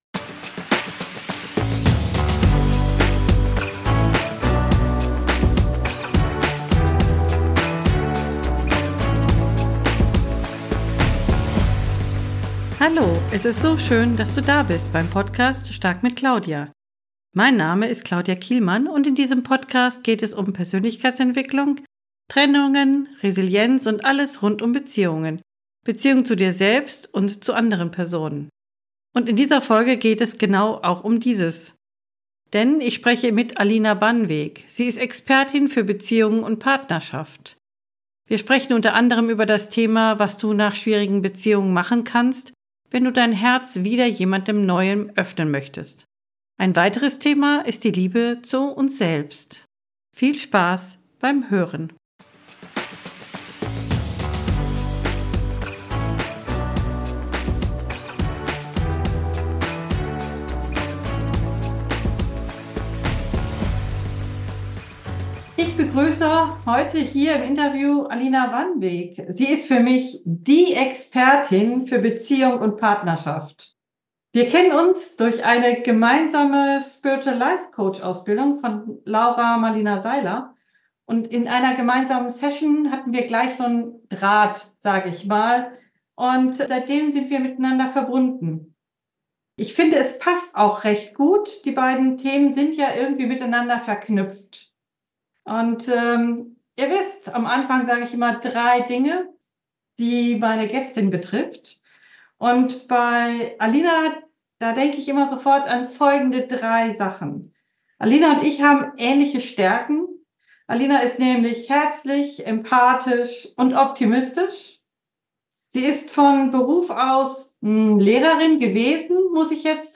#033 Wie kann ich nach einer Trennung mein Herz wieder öffnen? – Interview